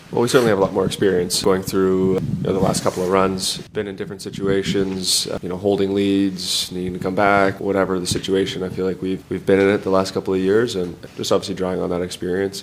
Oilers captain Connor McDavid spoke to media ahead of their Game 1 matchup and says this group has grown alot since their last loss in the conference finals to the Colorado Avalanche.